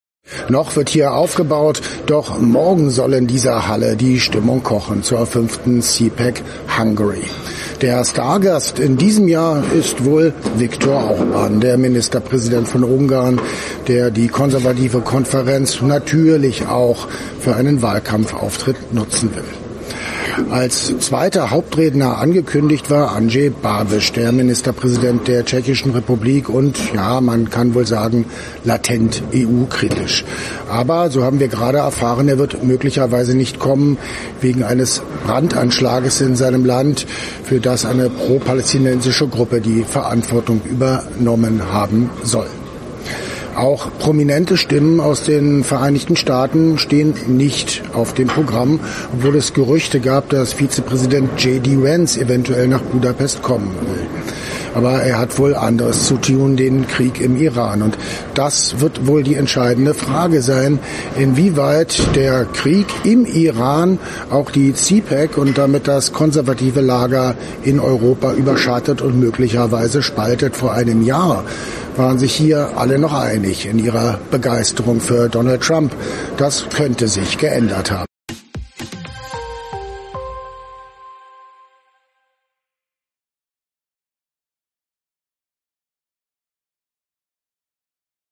CPAC 2026: AUF1 ist live dabei